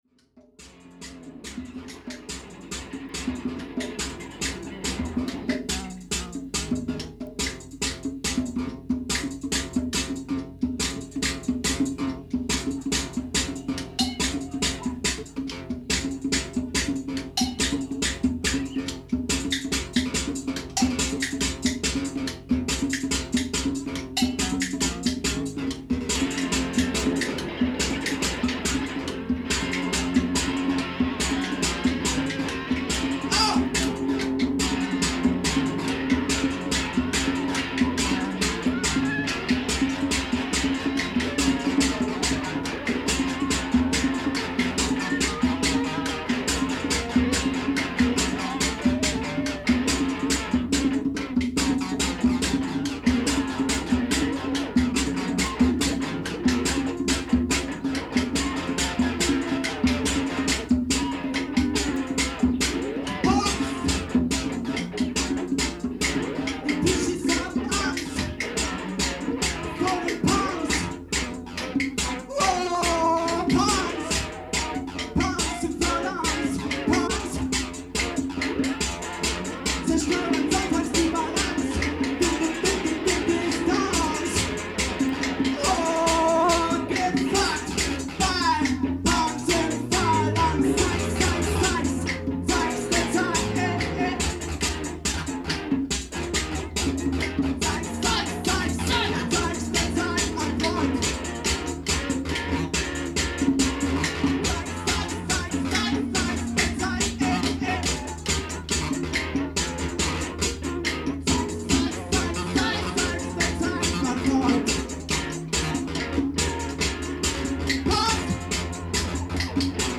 Erste Stimme und Perkussion
Zweite Stimme und Schlagholz
Syn, 606
Gitarre
Congas
Neue deutsche Tanzmusik, live vor Publikum im Oktober 1983